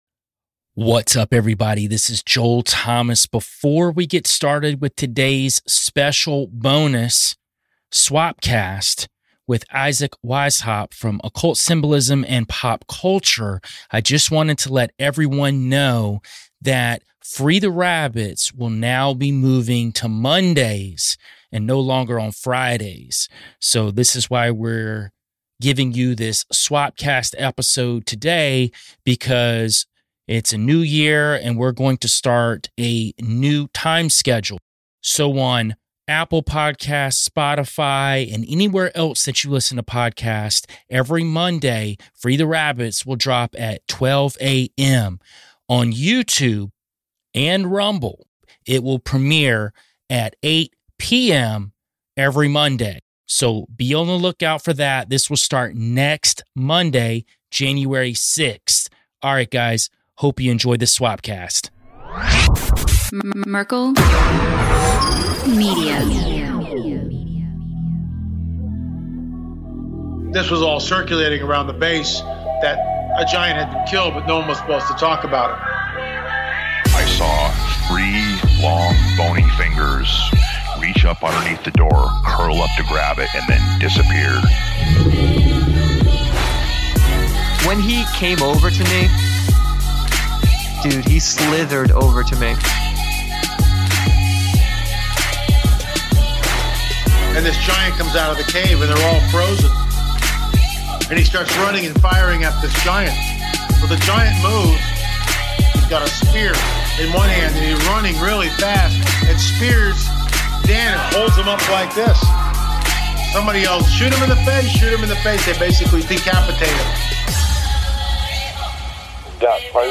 OUTRO MUSIC